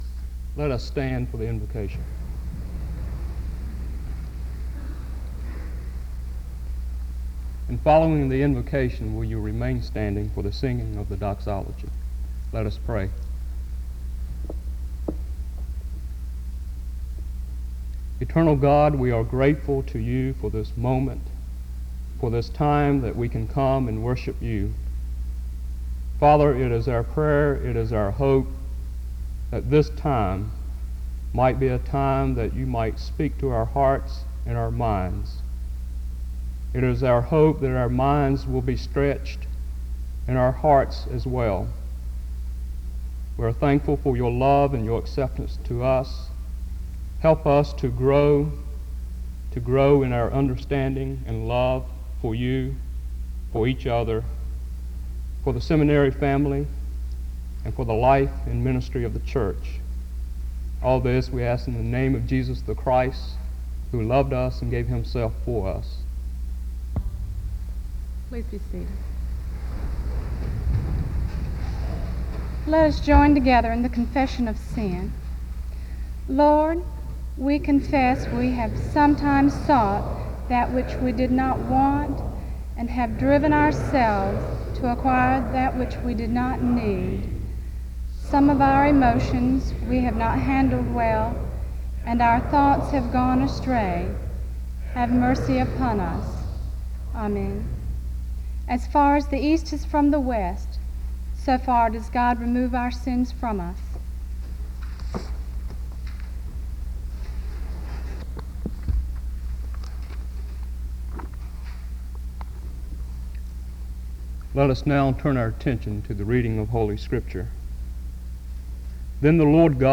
The service starts with a prayer from 0:00-1:06. A confessional prayer is offered from 1:13-1:46.
A scripture reading takes place from 1:54-2:32.